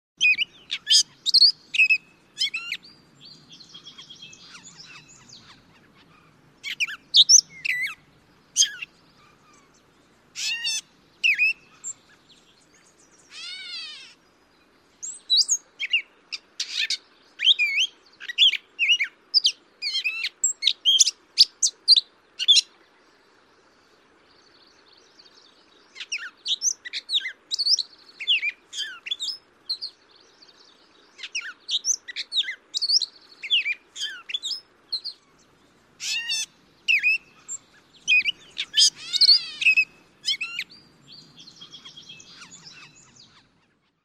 • Their calls include the catlike meow call that gives them their name.
Bird Sound
The most common call is a raspy mew that sounds like a cat. Catbirds also make a loud, chattering chek-chek-chek and a quiet quirt.
GreyCatbird.mp3